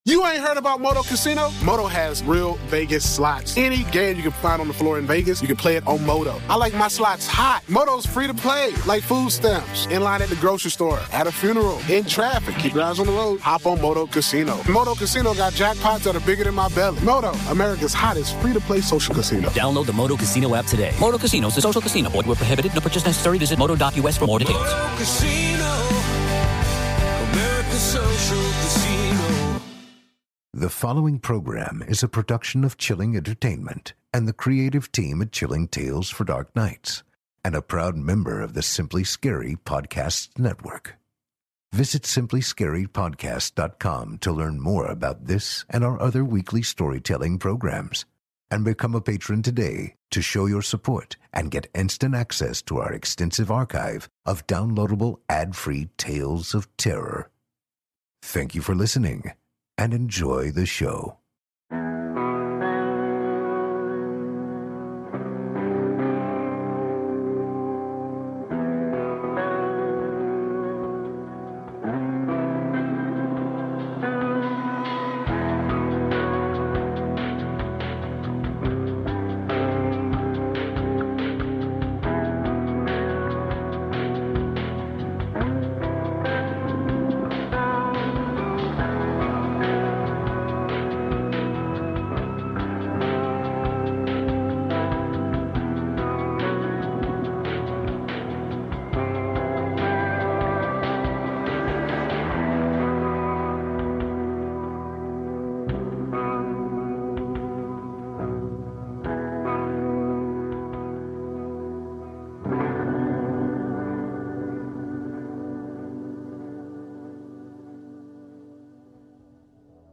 Tonight, I’ll be presenting two stories, both by newcomers to the show.